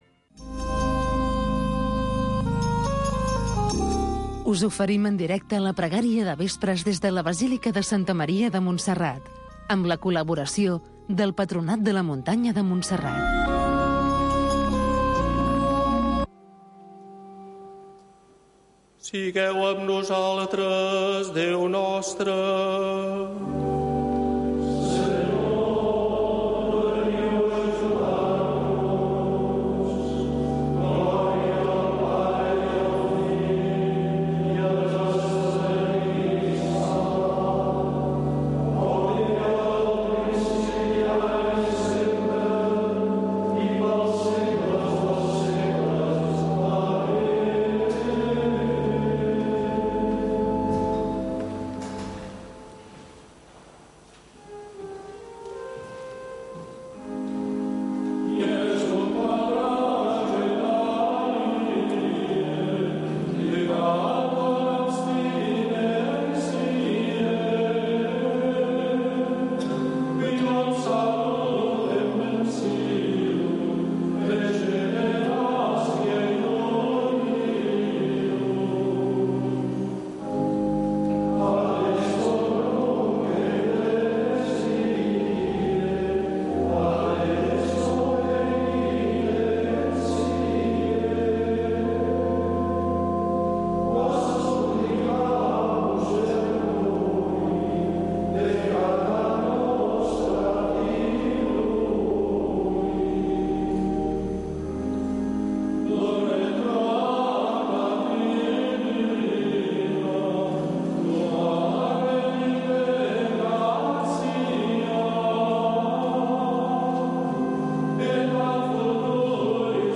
Pregària del vespre per donar gràcies al Senyor. Amb els monjos de Montserrat, cada dia a les 18.45 h